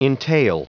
Prononciation du mot entail en anglais (fichier audio)
Prononciation du mot : entail